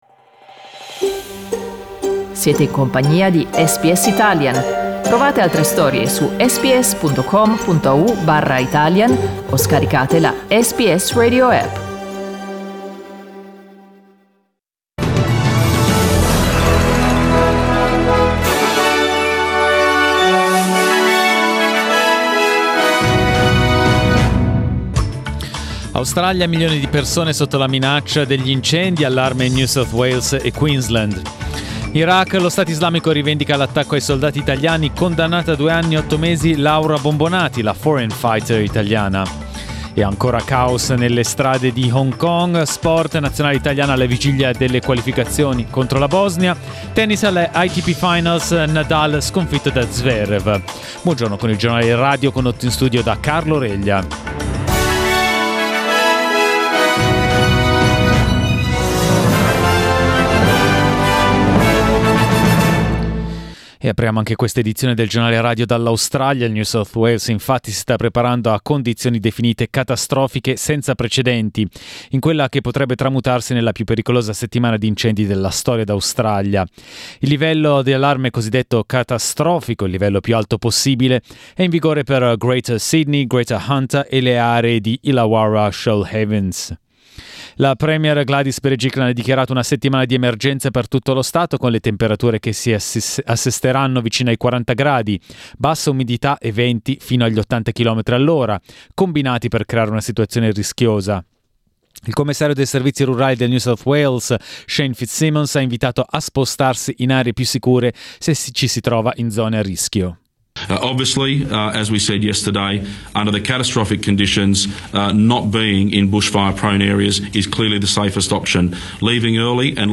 Our news bulletin (in Italian).